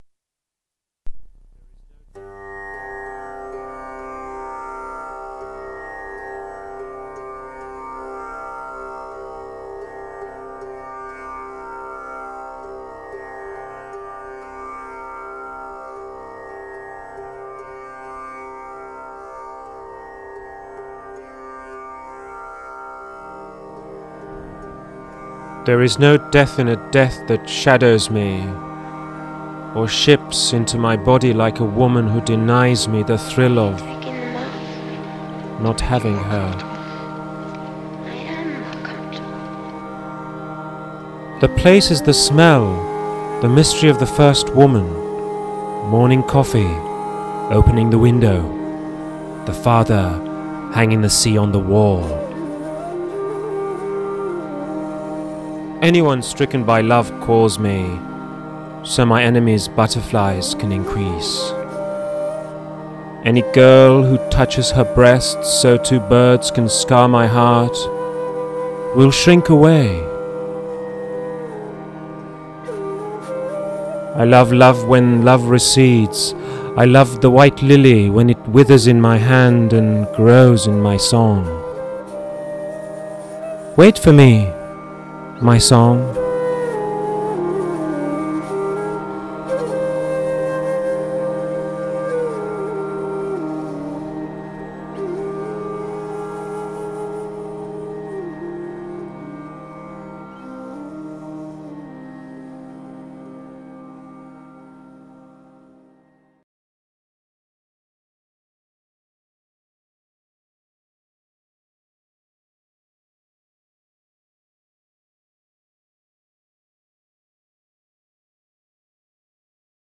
برای شنیدن صدای شاعر